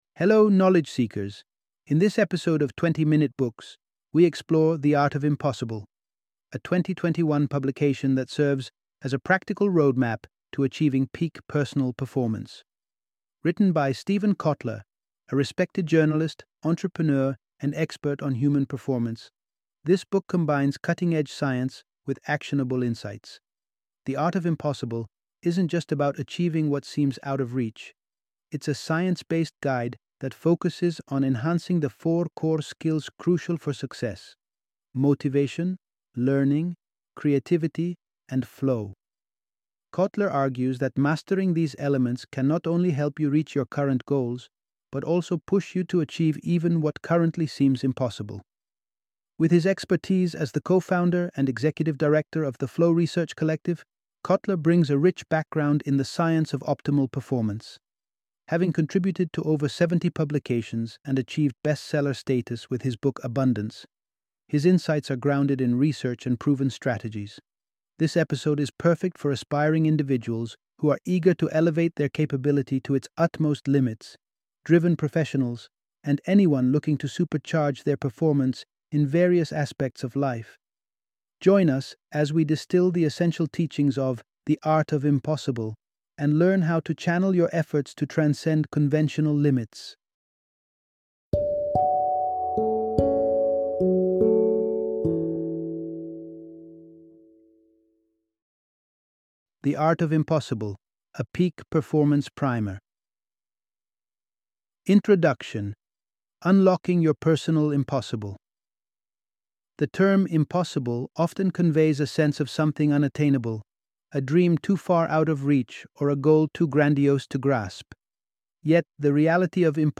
The Art of Impossible - Audiobook Summary